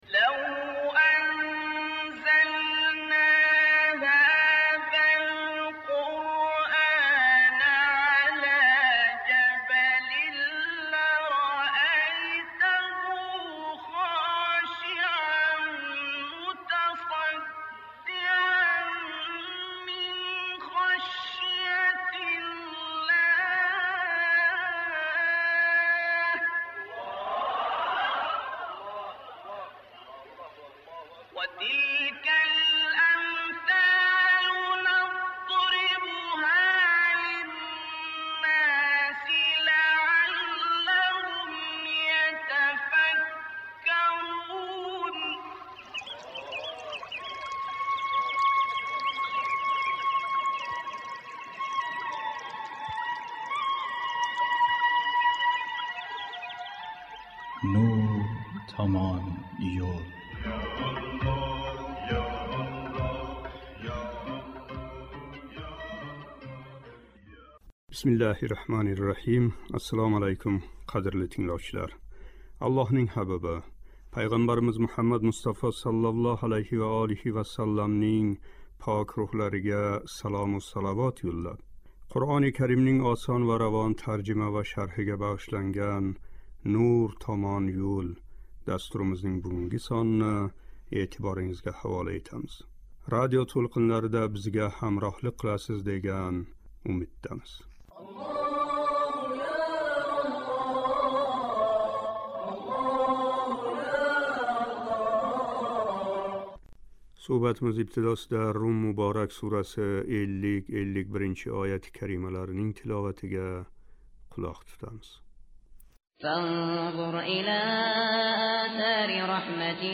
" Рум " муборак сураси 50-54-ояти карималарининг шарҳи. Суҳбатимиз ибтидосида « Рум " муборак сураси 50-51-ояти карималарининг тиловатига қулоқ тутамиз